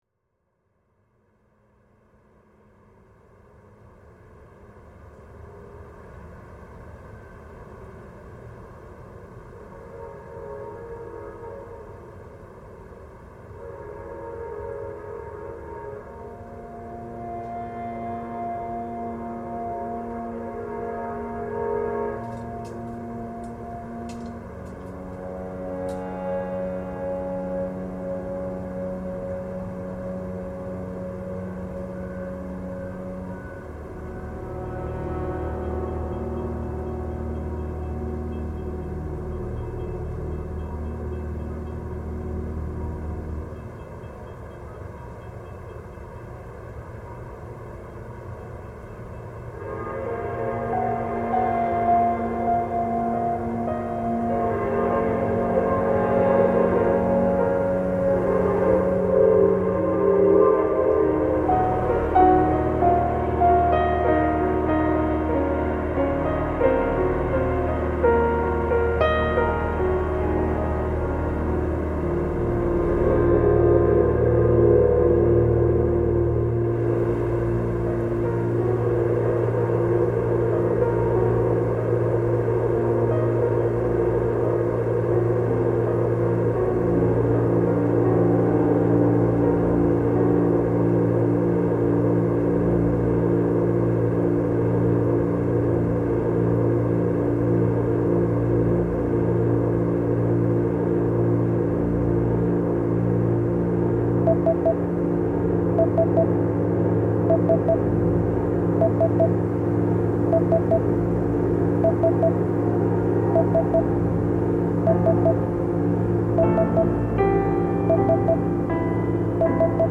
Savannah Garden City soundscape reimagined